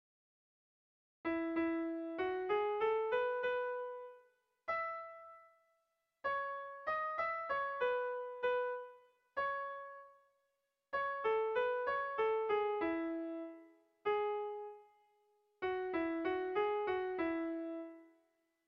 Irrizkoa
Lauko txikia (hg) / Bi puntuko txikia (ip)
AB